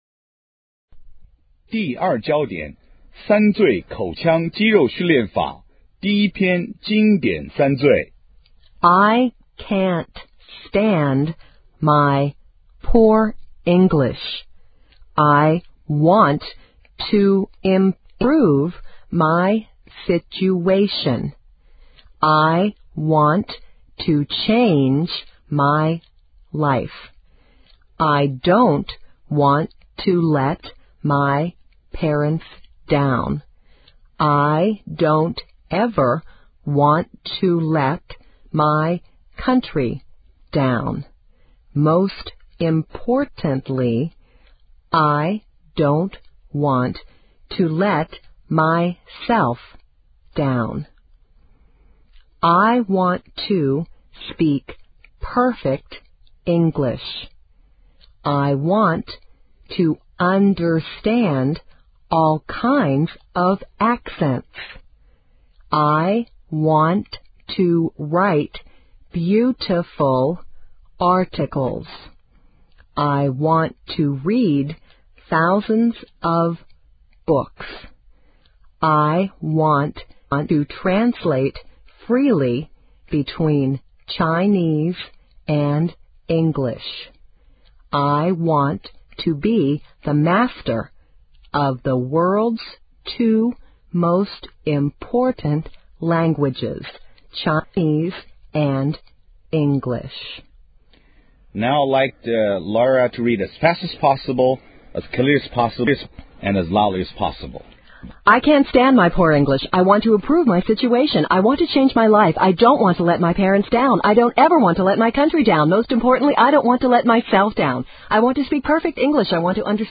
用“最大声、最清晰、最快速”的办法反复操练句子或小短文直至脱口而出。